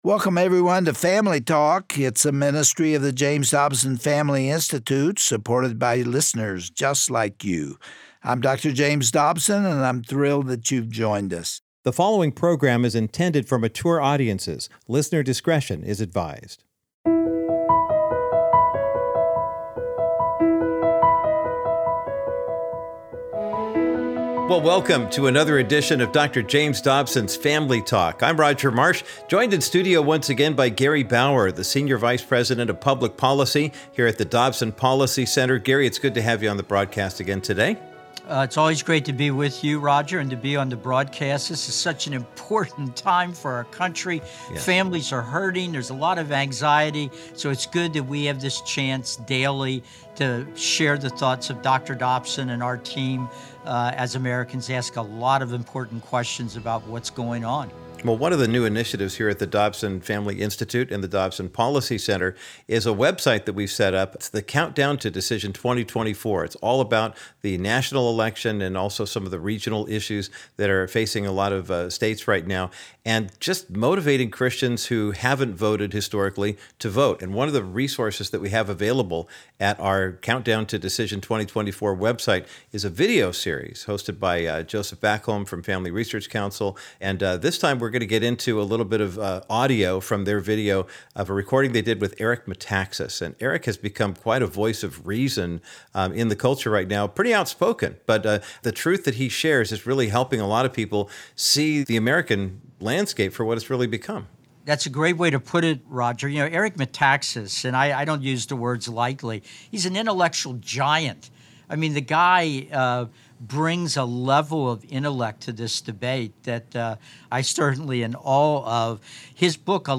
On today’s edition of Family Talk, you’ll hear from Eric Metaxas, who is an acclaimed author, speaker, and host of The Eric Metaxas Show. He passionately shares his views on the ever-deepening spiritual warfare that our country is experiencing, and the responsibilities that God has placed on His church.